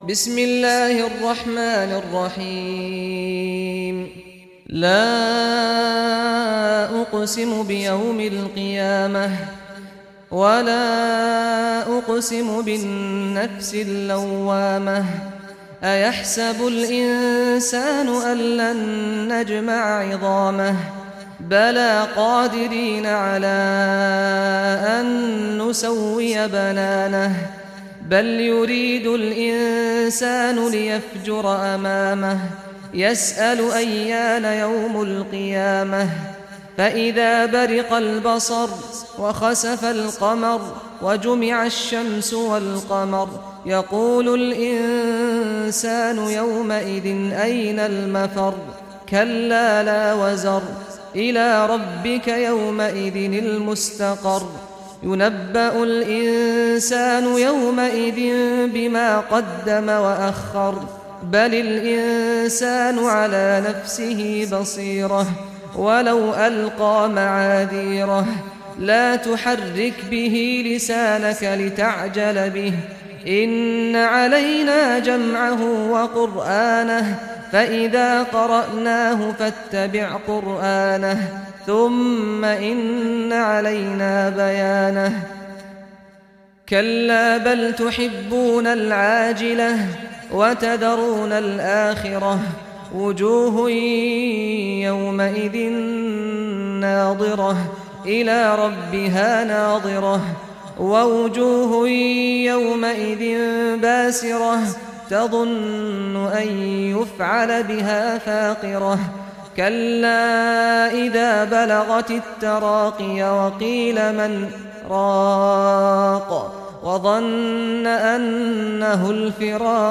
Surah Al-Qiyamah Recitation by Sheikh Saad Ghamdi
Surah Al-Qiyamah, listen or play online mp3 tilawat / recitation in Arabic in the beautiful voice of Sheikh Saad al Ghamdi.